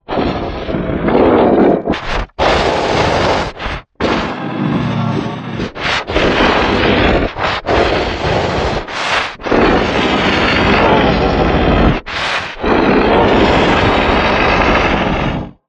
Demon Growl Sound
horror
Demon Growl